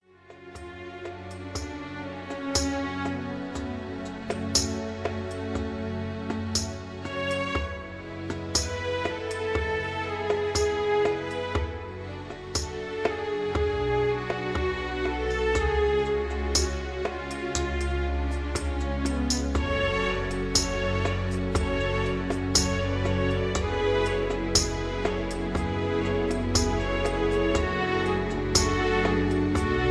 (Key-F#) Karaoke MP3 Backing Tracks